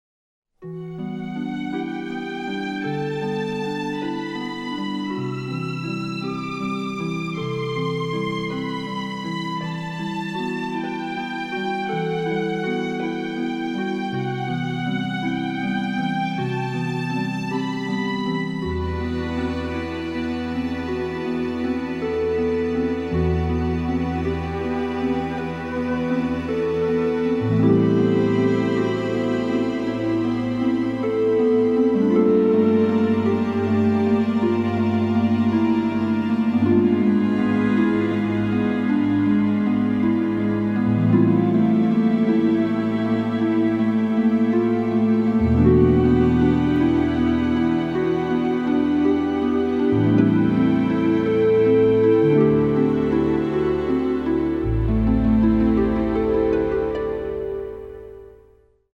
CD soundtrack
Mysterious, romantic and filled with drama and apprehension.